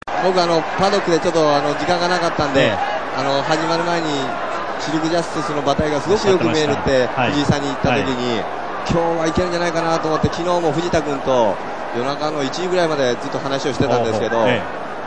(田原成貴・第42回有馬記念でのNHK実況席にて)